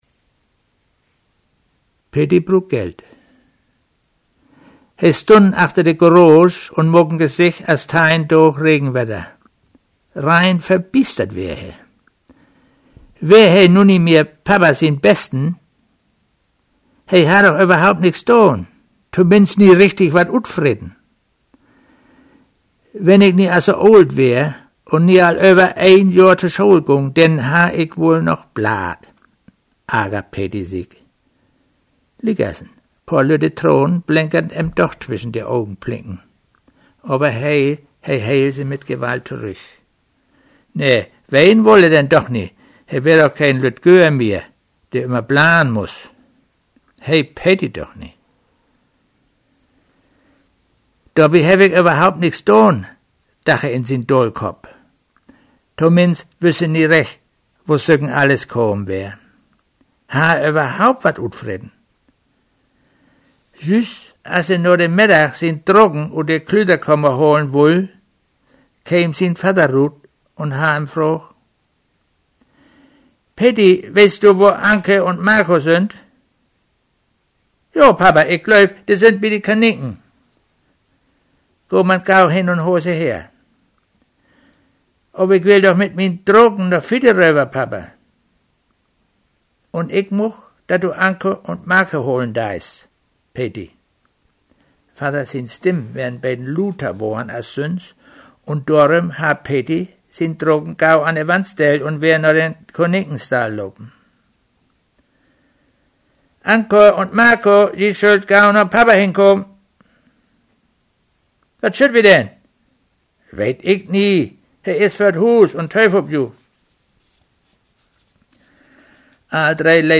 Idiom: Zentral-Schleswig-Holsteinisches Platt (Rendsburg)